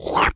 pgs/Assets/Audio/Animal_Impersonations/lizard_tongue_lick_01.wav at master
lizard_tongue_lick_01.wav